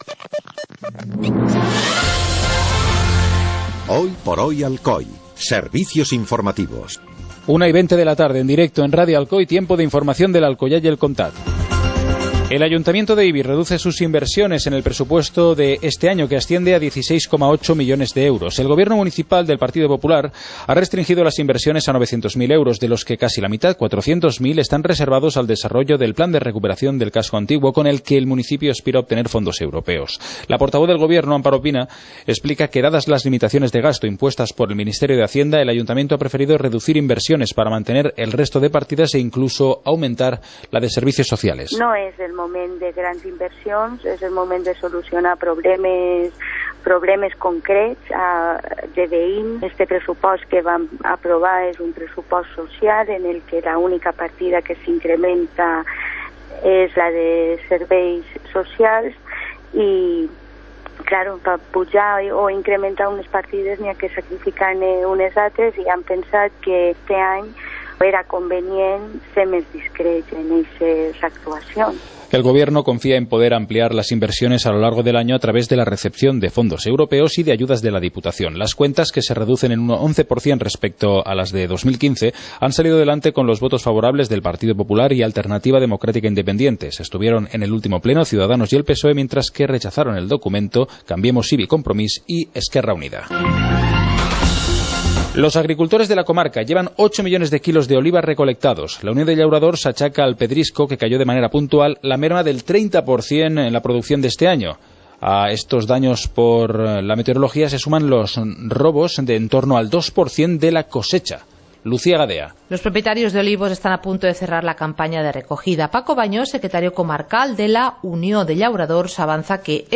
Informativo comarcal - jueves, 21 de enero de 2016